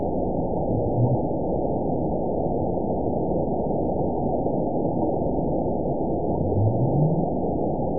event 920105 date 02/23/24 time 00:31:41 GMT (1 year, 2 months ago) score 9.71 location TSS-AB02 detected by nrw target species NRW annotations +NRW Spectrogram: Frequency (kHz) vs. Time (s) audio not available .wav